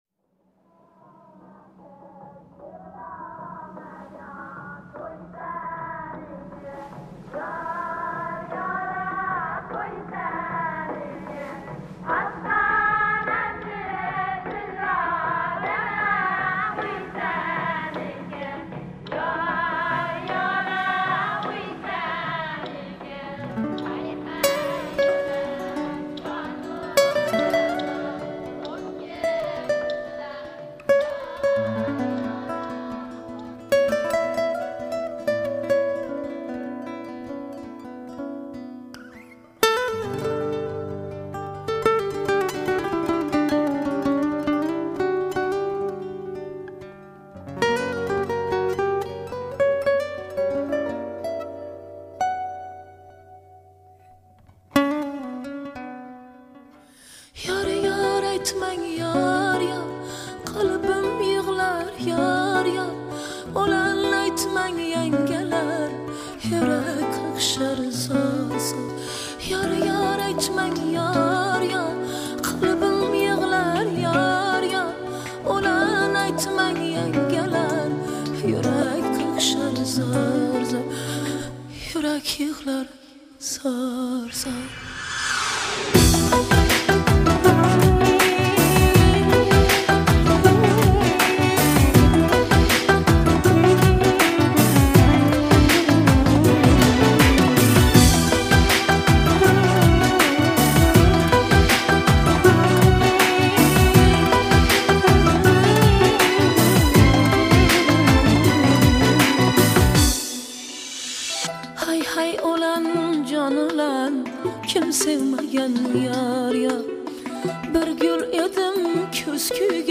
узбекской певицы
современное звучание с народными инструментами